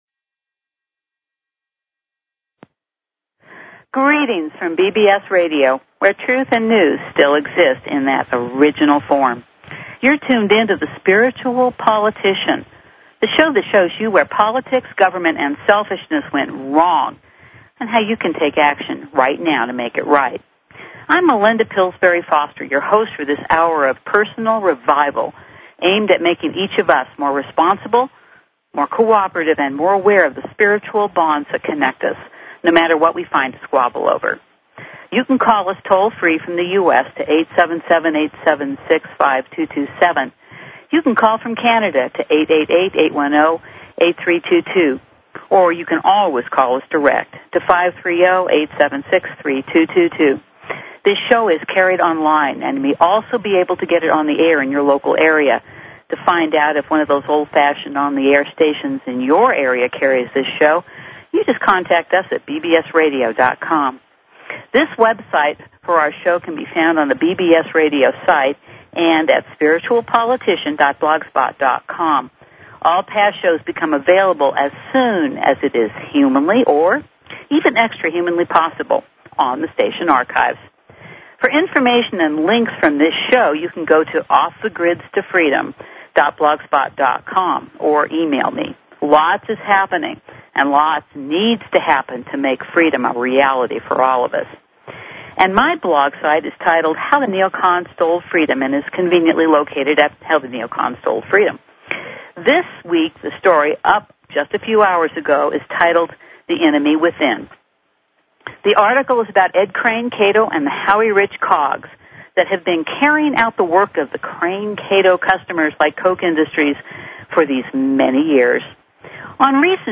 Talk Show Episode, Audio Podcast, Spiritual_Politician and Courtesy of BBS Radio on , show guests , about , categorized as
Hear Sheriff Richard Mack this Friday on the Spiritual Politician